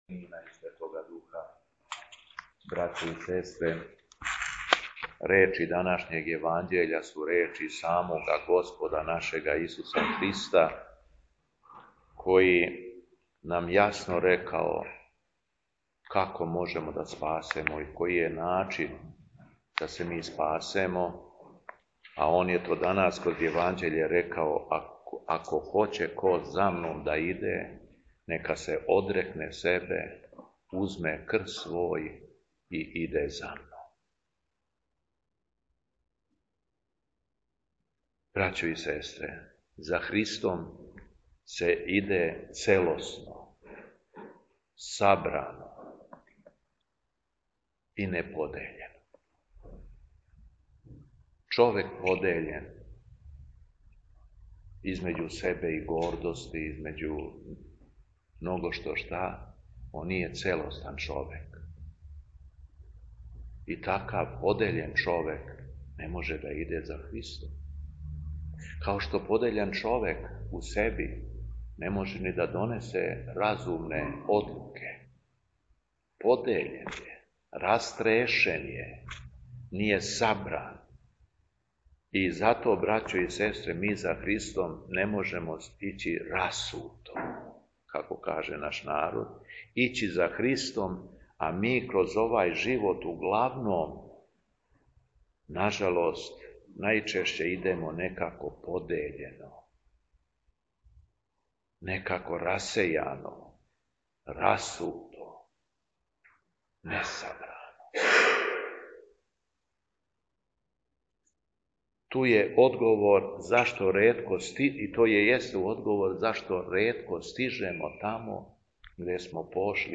У осми четвртак по Духовима 31. јула 2025. године, Његово Високопресвештенство Митрополит шумадијски Г. Јован служио је Свету Литургију у Старој Цркви у Крагујевцу уз саслужење братства овога светога храма.
Беседа Његовог Високопреосвештенства Митрополита шумадијског г. Јована
Беседом се верном народу обратио Високопреосвећени Митрополит Јован рекавши да: